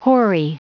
Prononciation du mot hoary en anglais (fichier audio)
Prononciation du mot : hoary